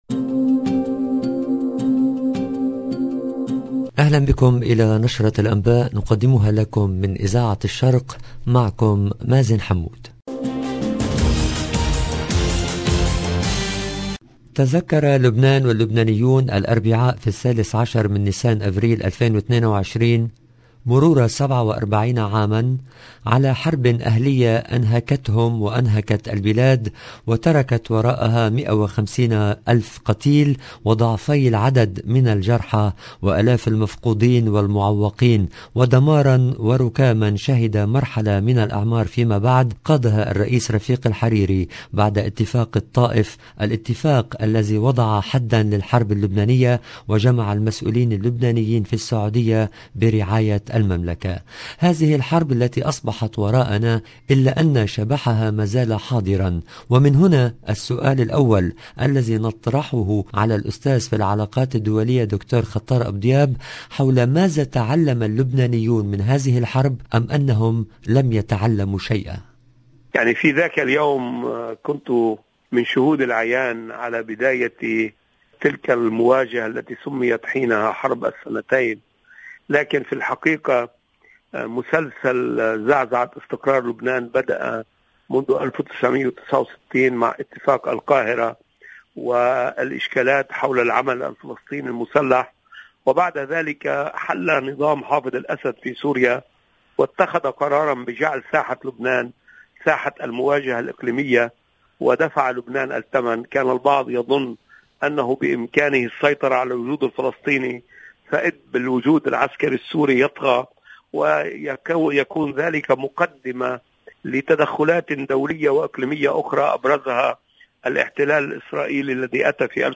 LE JOURNAL DU SOIR EN LANGUE ARABE DU 13/04/22